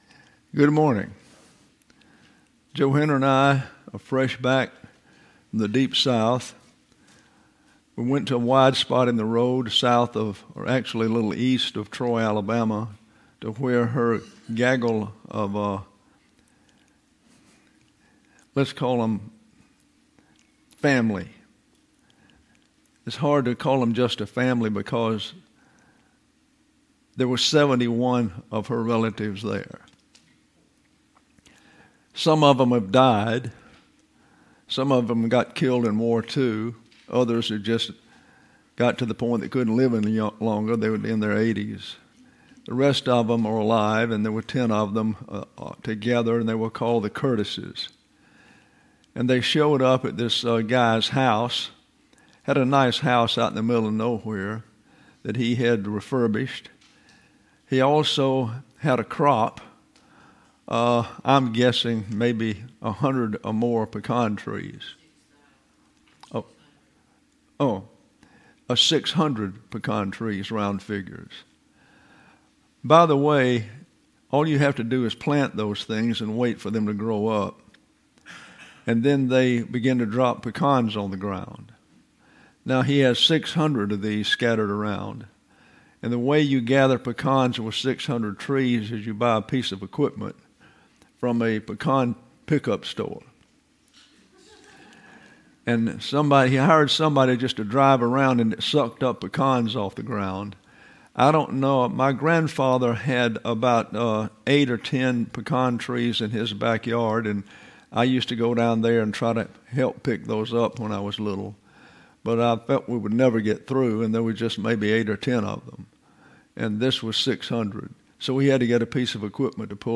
James Chapter Five: Lesson 73: Analysis of Verse 12: James 5:9: The Lord Standing at the Door and Knocking Is Warning Discipline: Stages of Divine Discipline: Details on the Sin Unto Death: The Context for the Sin Face-to-Face With Death;